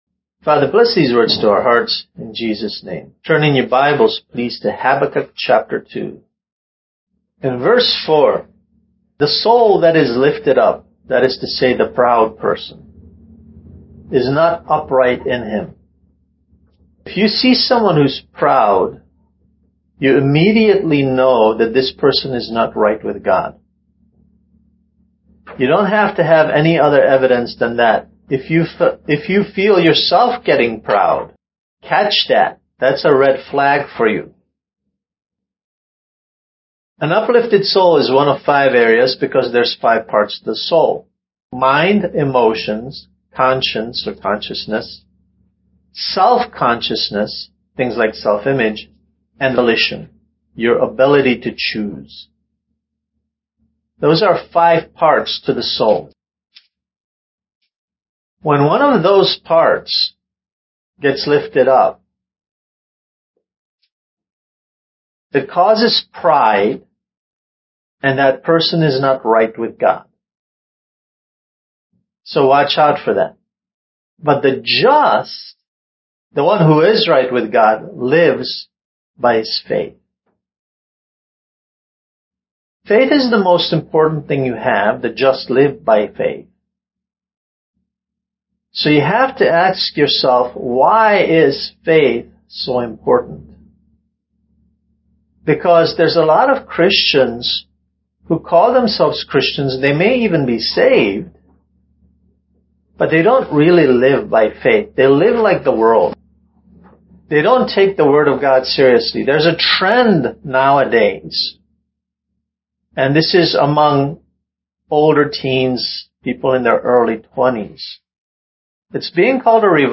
Kids Message: Why Faith is Important